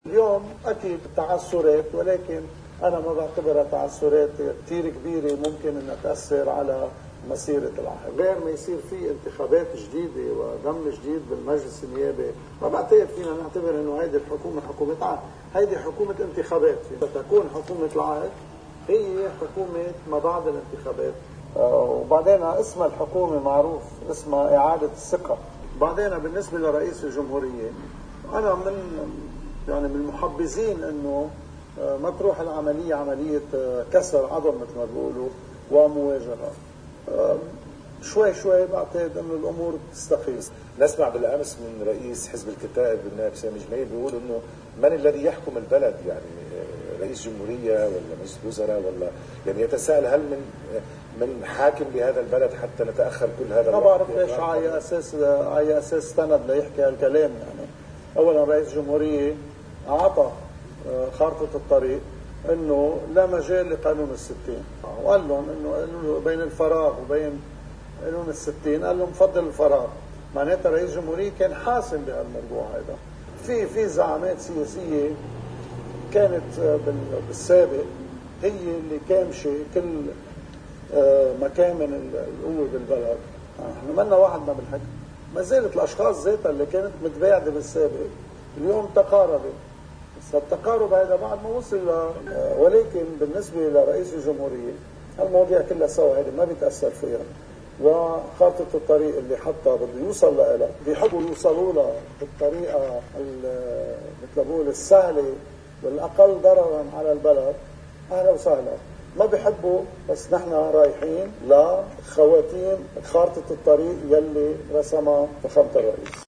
مقتطف من حديث النائب نبيل نقولا لقناة المنار: